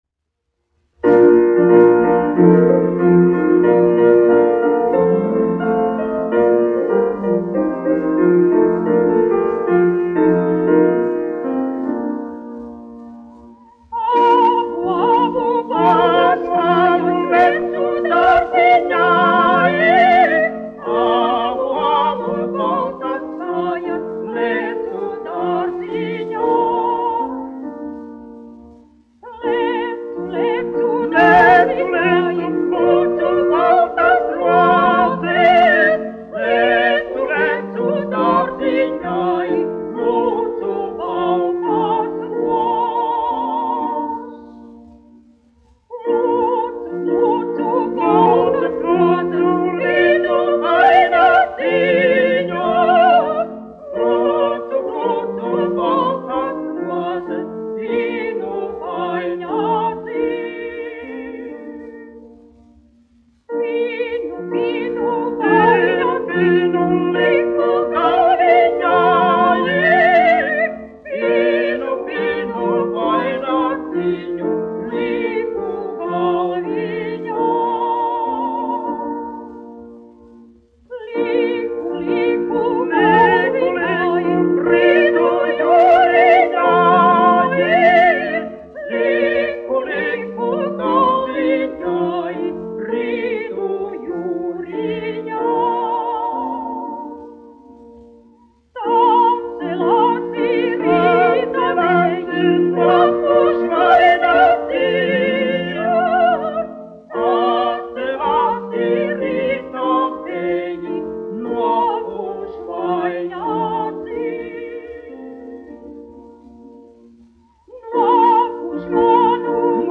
1 skpl. : analogs, 78 apgr/min, mono ; 25 cm
Latviešu tautasdziesmas
Vokālie dueti ar klavierēm
Skaņuplate
Latvijas vēsturiskie šellaka skaņuplašu ieraksti (Kolekcija)